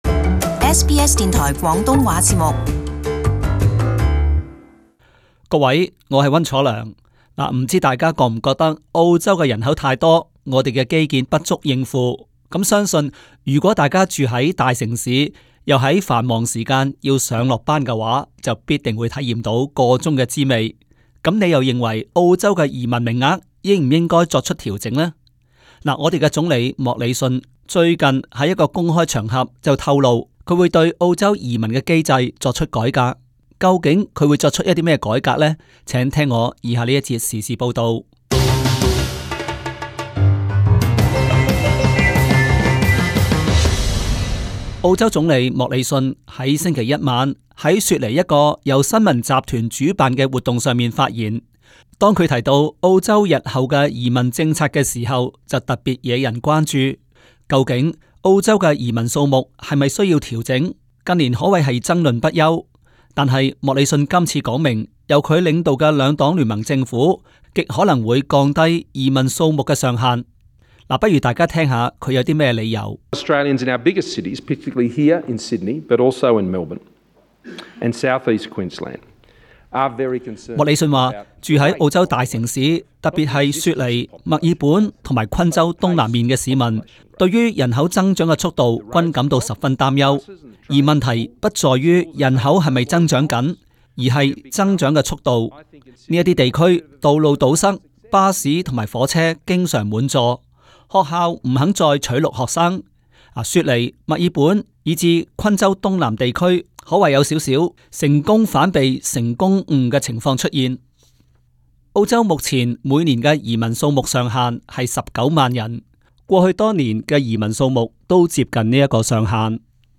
【時事報導】 莫里遜欲削減澳洲移民配額